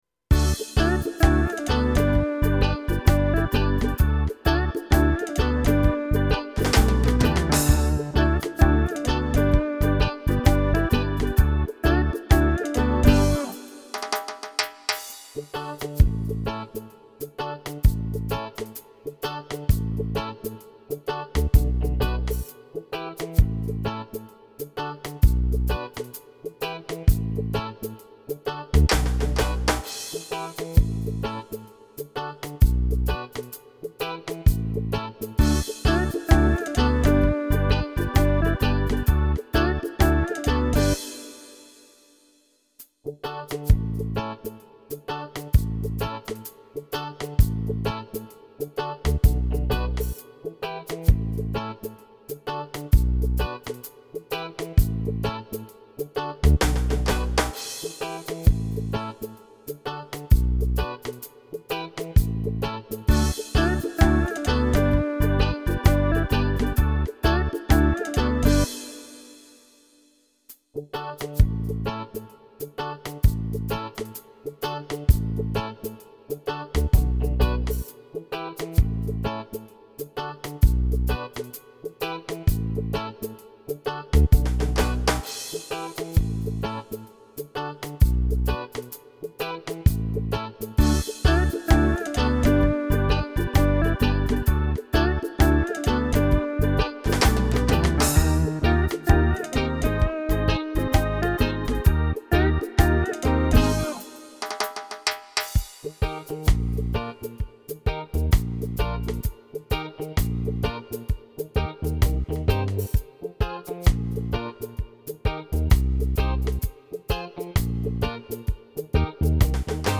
• Качество: Хорошее
• Категория: Детские песни
караоке
минусовка